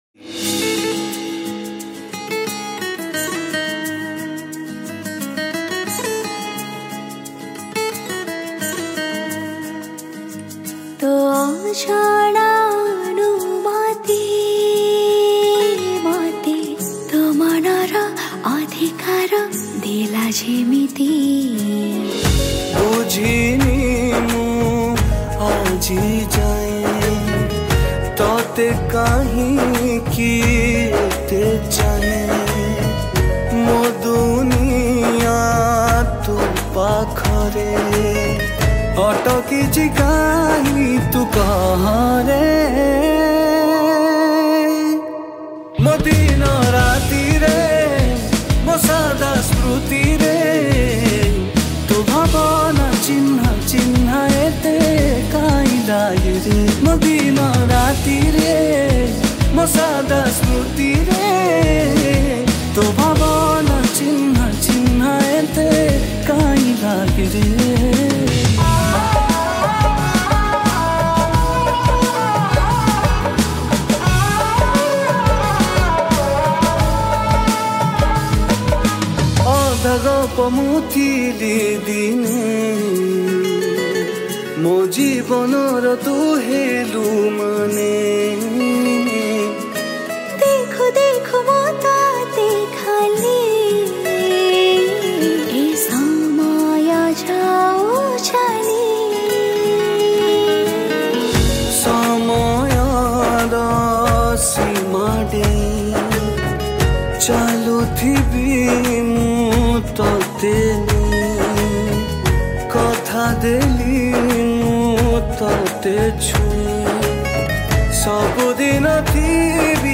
Guitar
Rhythm